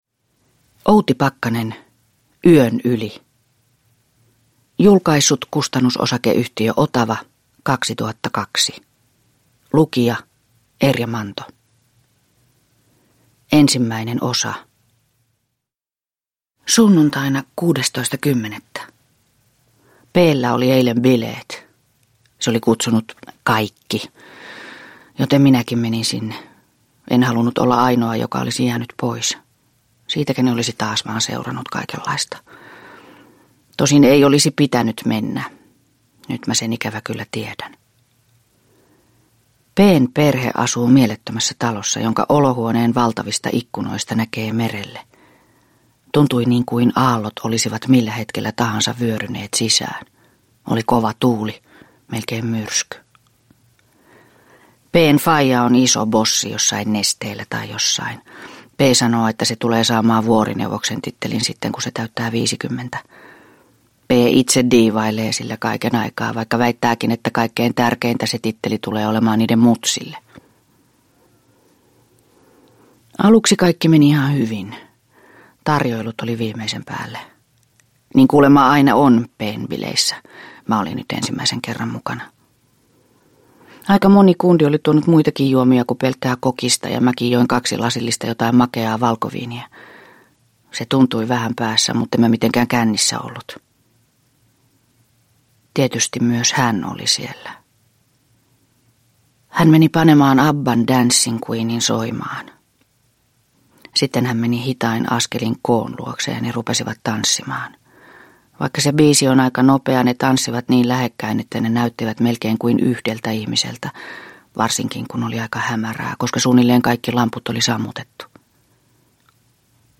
Yön yli – Ljudbok